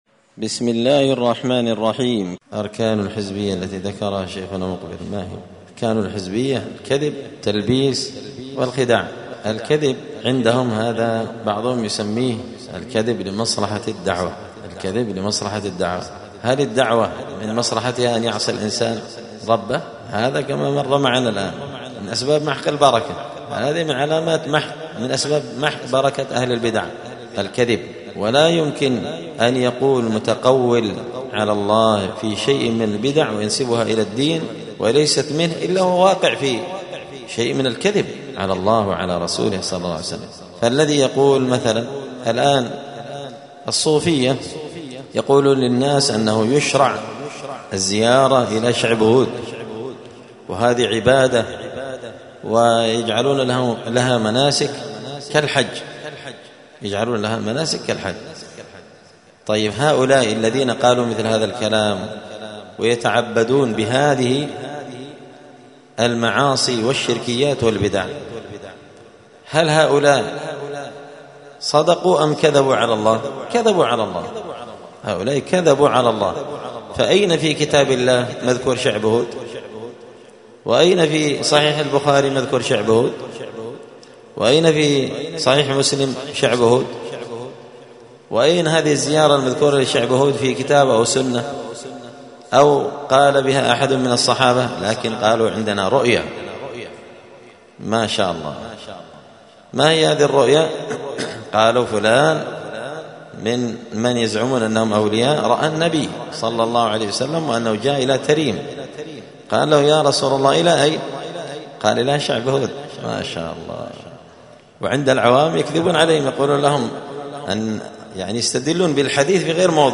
*{الصوفية وشعب هود}*نصائح ومقتطفات
دار الحديث بمسجد الفرقان ـ قشن ـ المهرة ـ اليمن